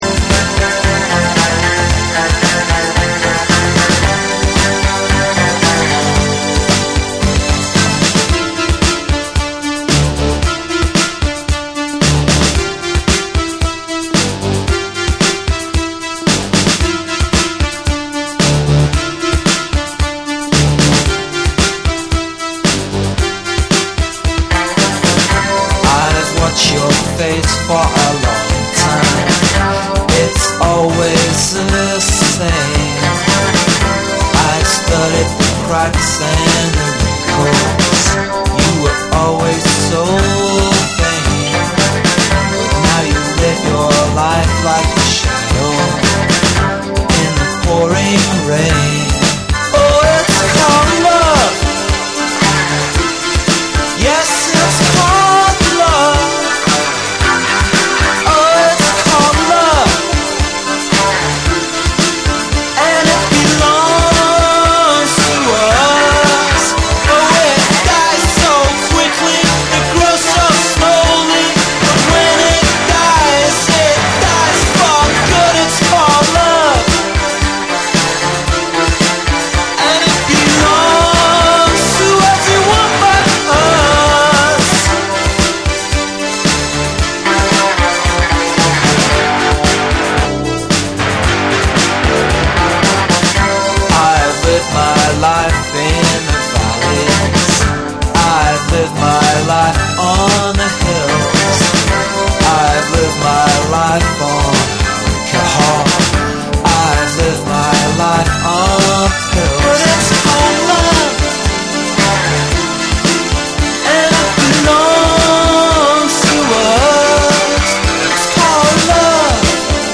シンセの反復フレーズとタイトなリズムが生むクールで高揚感のあるサウンドに、どこか切なさを帯びたメロディが重なる